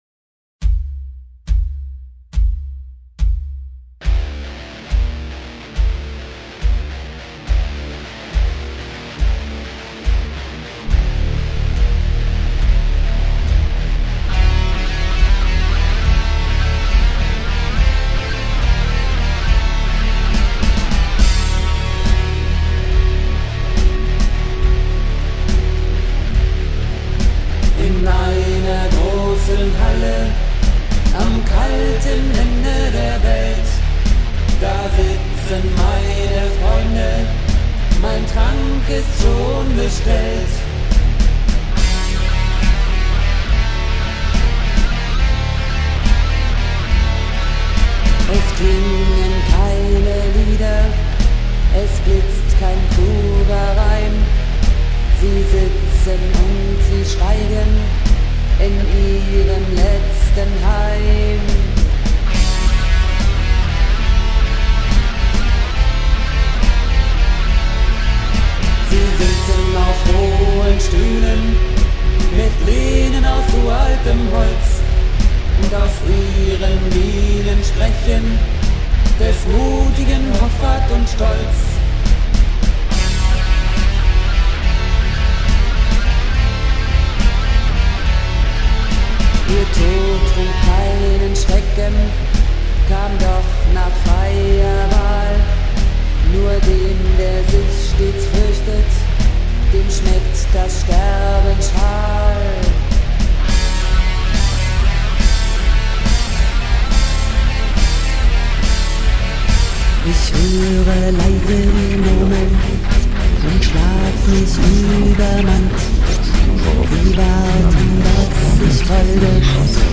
Kalte Tode (Demo) (C) 2009 zur Übersicht
Gesang